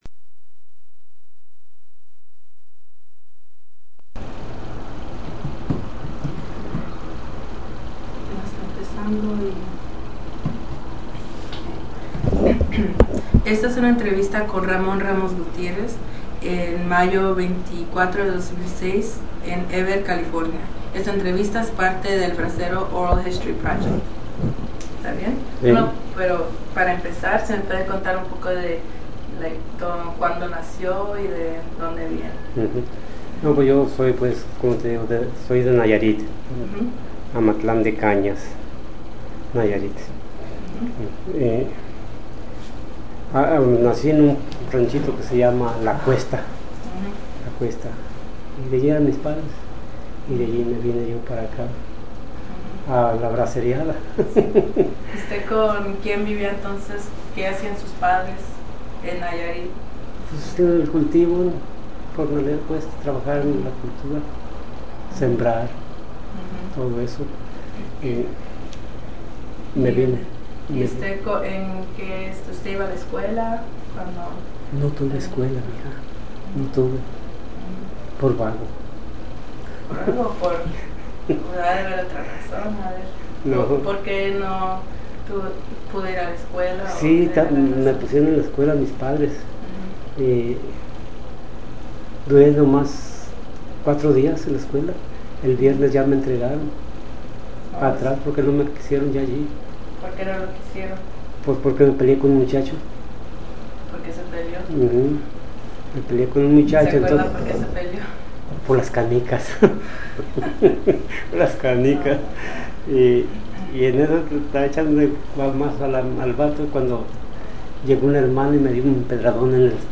Original Format Mini disc
Location Heber, CA